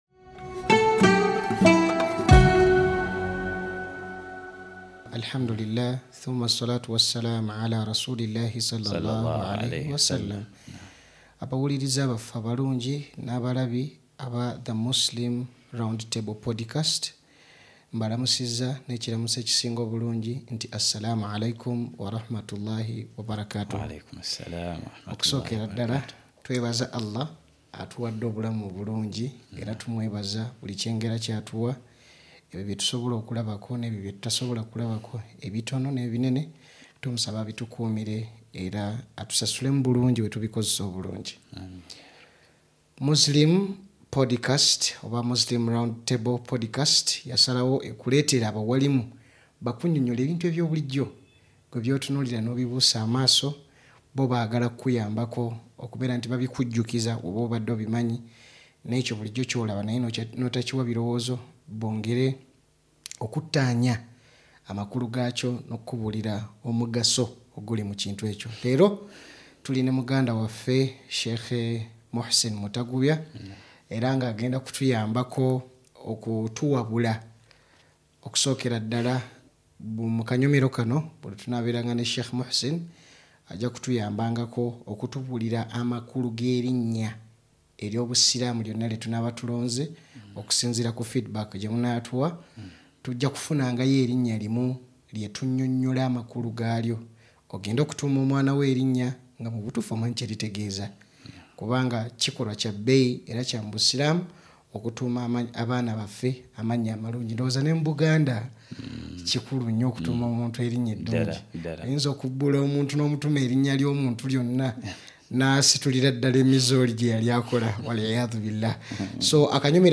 Location: AAA Complex, Bukoto – Kisaasi road